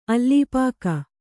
♪ allīpāka